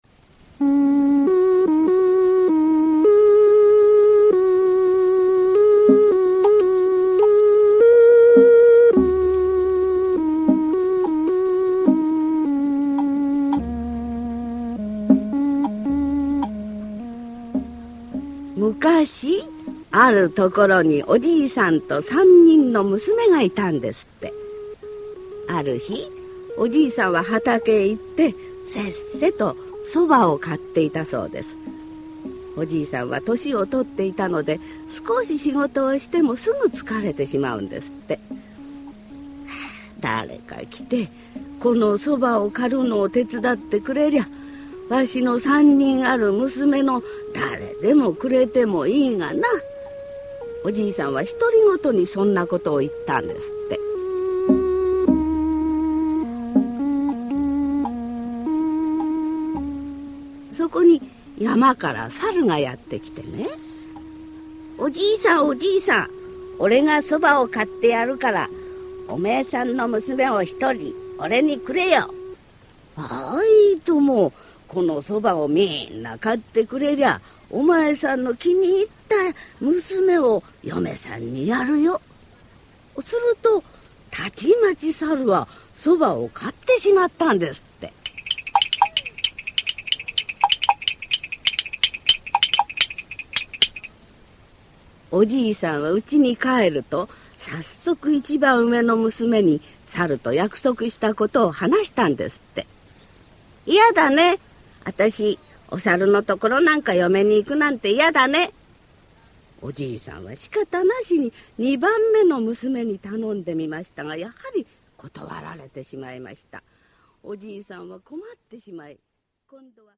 [オーディオブック] 三人むすめ